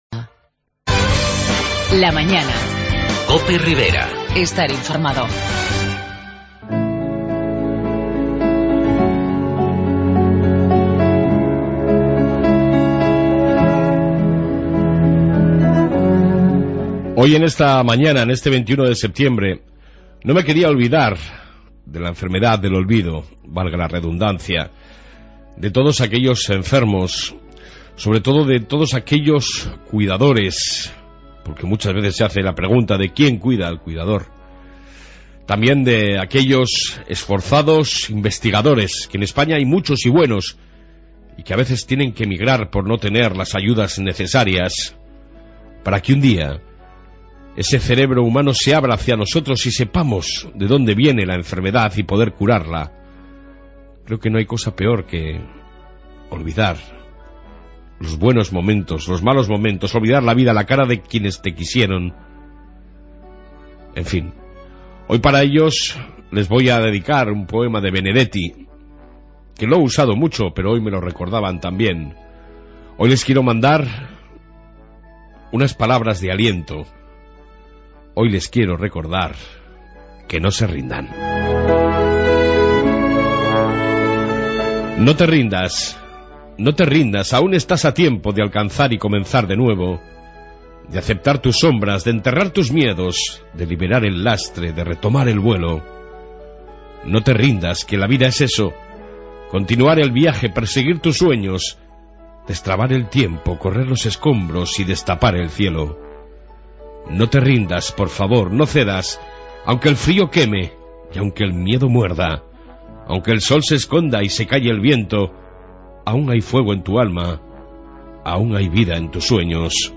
Reflexión Matutina dedicada a enfermos ,cuidadores, e investigadores en el dia del alzheimer, Informe Policía Municipal, declaraciones sobre la reunión de Uxue Barkos y Eneko larrarte y reportaje en la Plaza nuevacon AFAN (asociación de familiares de enfermos de Alzheimer de Navarra)